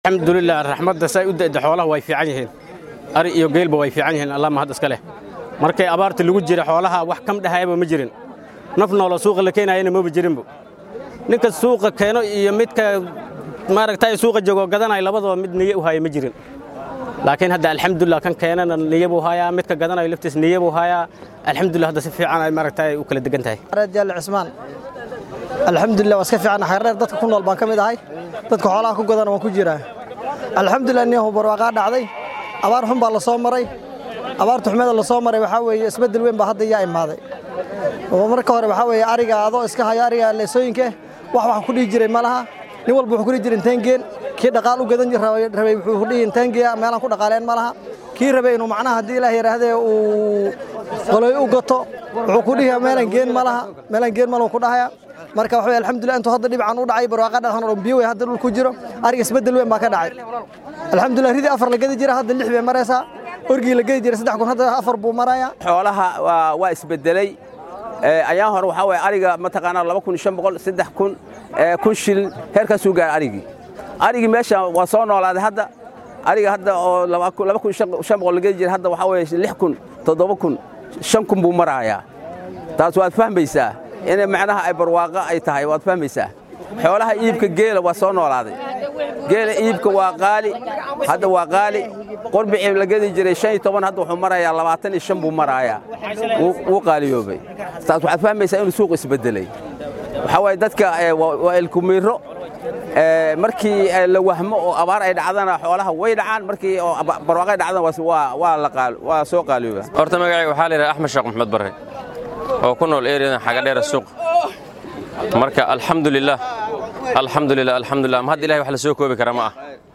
Waa kuwaan qaar ka mid ah suuqleyda iyo ganacsatada xoolaha ee xeryaha qaxootiga ee Dadaab oo ka hadlaya arrintan